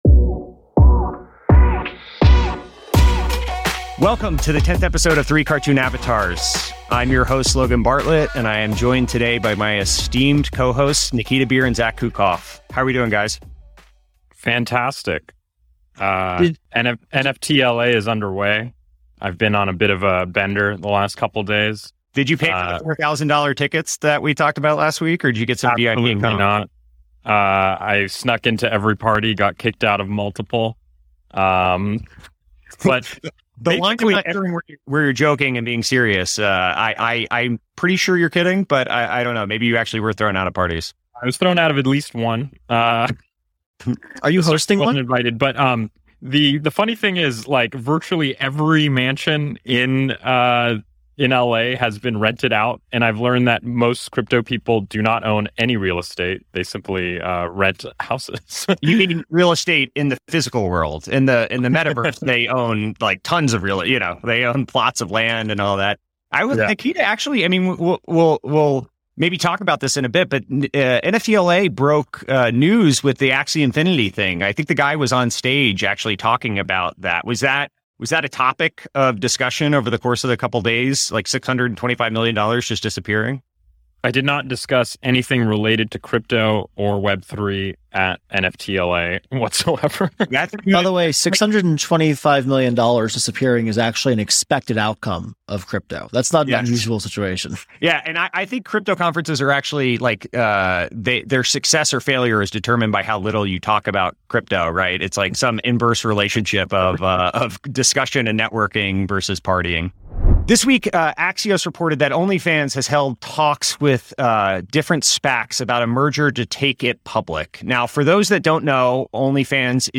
EP 10: Interview